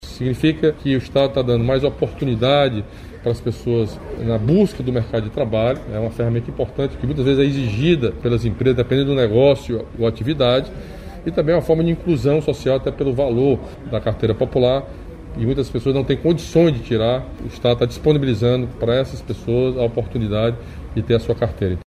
O programa combina o compromisso em garantir um trânsito mais seguro e a geração de oportunidades produtivas para a população, segundo o governador.